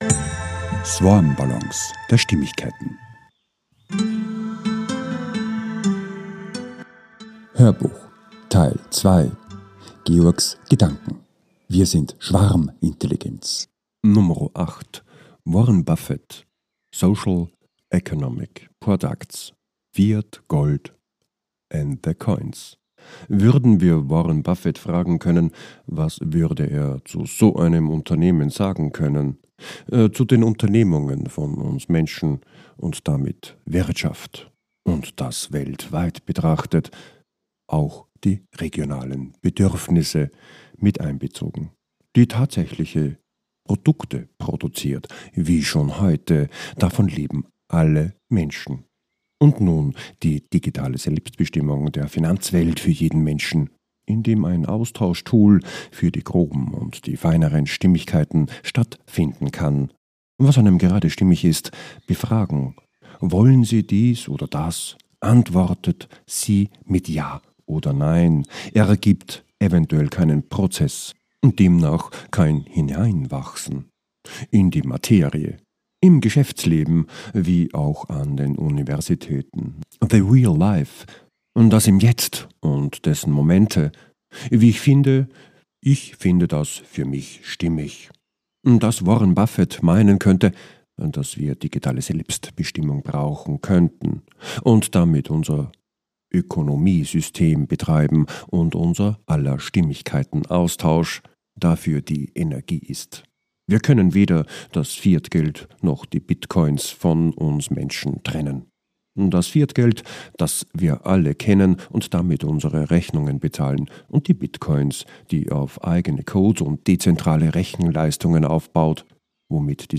HÖRBUCH TEIL 2 - 08 - WIR SIND SCHWARMINTELLIGENZ 2 - WARREN BUFFETT - ECO & SOC PRODUCTS? - 07.02.26, 14.31 ~ SwarmBallons A-Z der Stimmigkeit Podcast